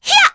Hanus-Vox_Attack3.wav